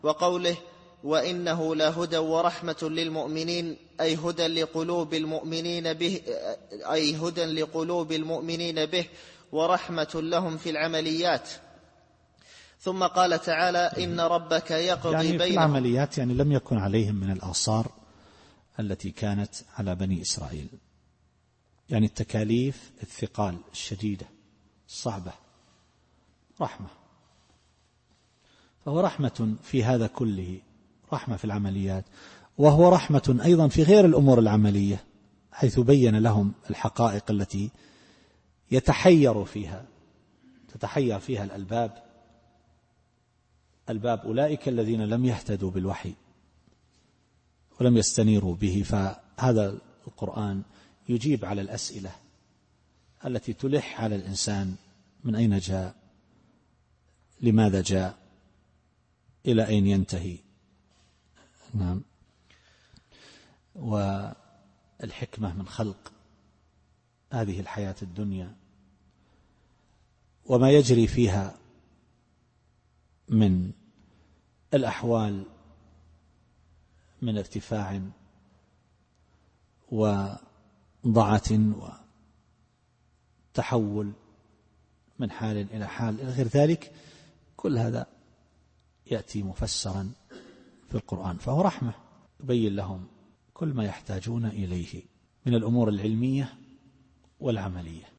التفسير الصوتي [النمل / 77]